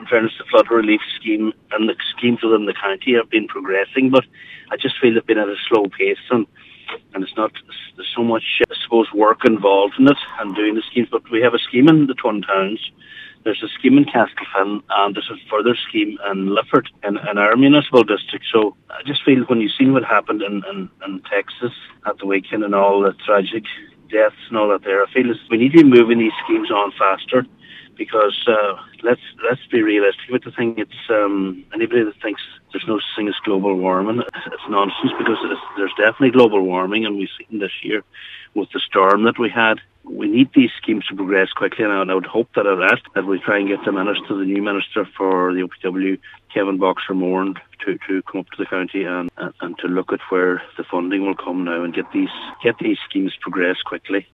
Cllr Harley says it’s time people stop doubting the real effects of global warming: